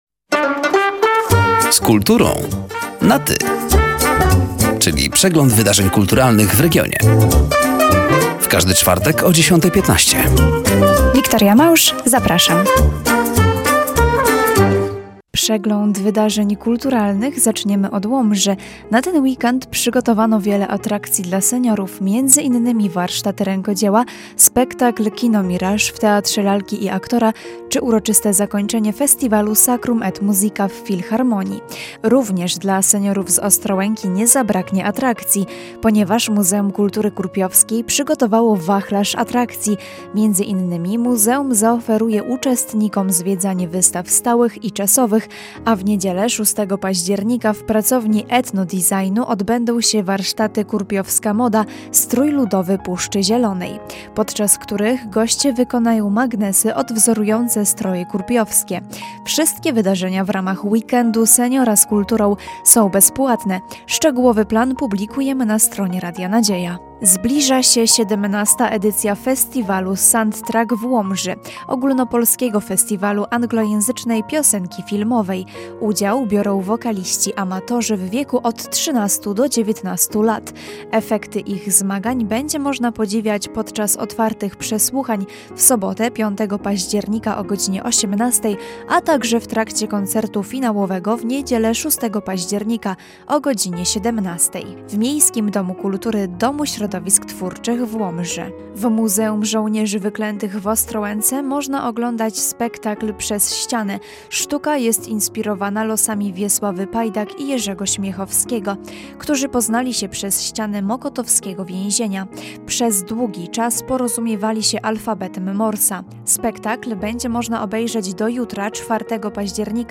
Zapraszamy do wysłuchania rozmowy i zapoznania się ze zbliżającymi wydarzeniami: